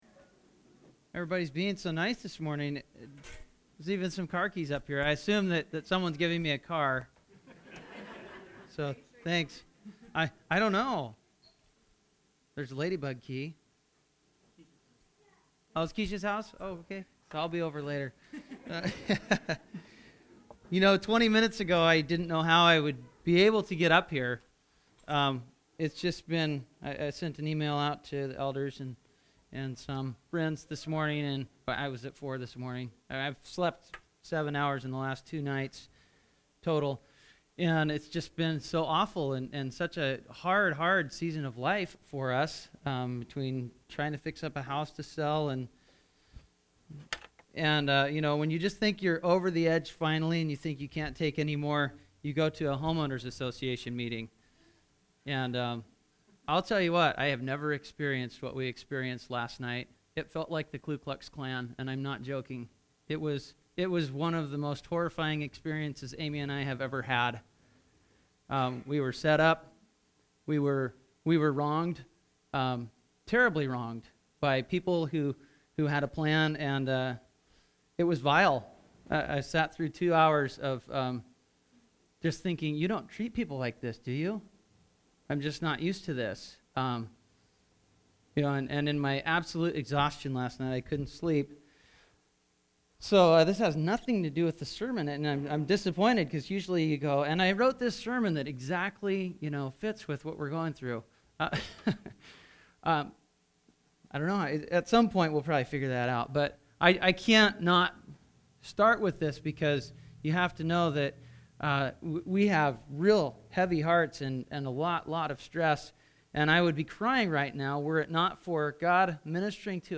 Sermons - Wapato Valley Church